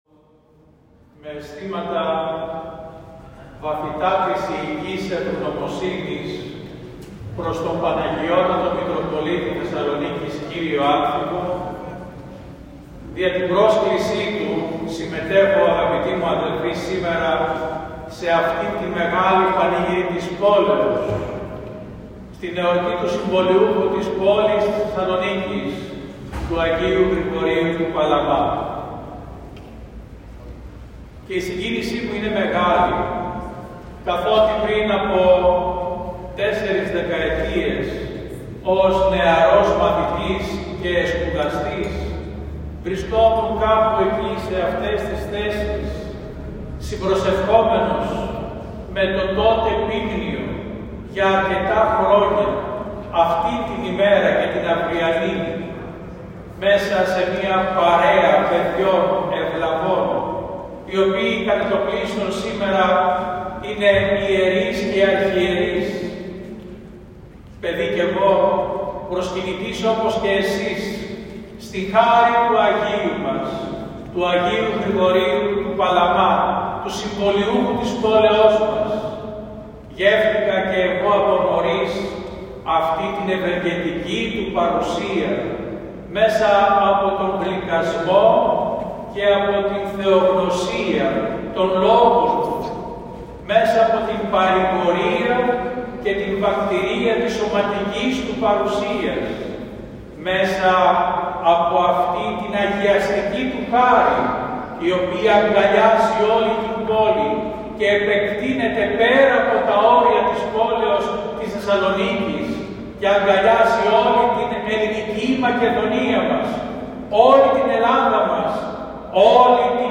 Με την προσήκουσα λαμπρότητα τελέσθηκε το απόγευμα της 19ης Μαρτίου, ο Μέγας Πανηγυρικός Αρχιερατικός Εσπερινός, επί τη εορτή του Αγίου Γρηγορίου του Παλαμά, Αρχιεπισκόπου Θεσσαλονίκης του Θαυματουργού, στον πανηγυρίζοντα φερώνυμο Ιερό Μητροπολιτικό Ναό της Αγιοτόκου πόλεως της Θεσσαλονίκης.
Ο Μητροπολίτης Πολυανής κ. Βαρθολομαίος στο κήρυγμα του ευχαρίστησε τον Παναγιώτατο Μητροπολίτη Θεσσαλονίκης κ. Άνθιμο και στη συνέχεια αναφέρθηκε στον Άγιο Γρηγόριο τον Παλαμά.